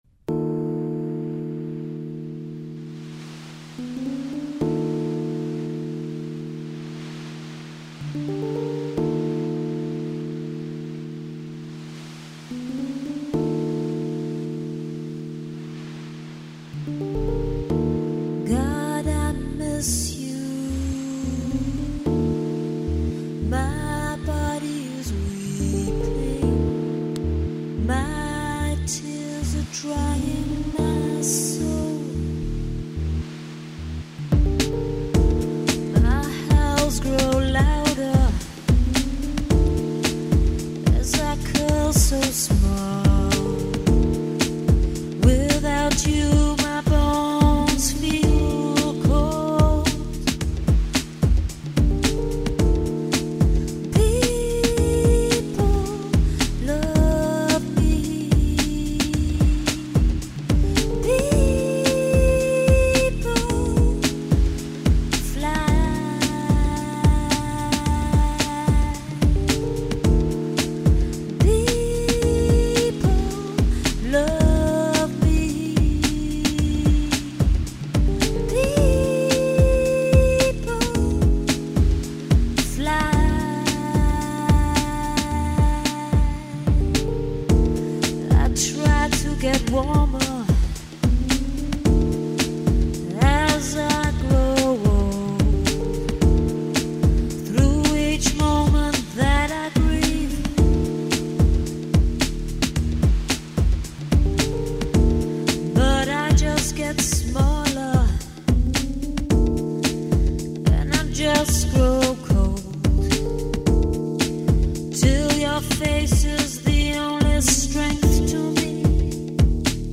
Here are some demo tapes that survived from back in the days...